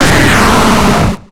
Cri de Tyranocif dans Pokémon X et Y.